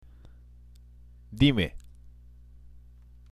＜発音と日本語＞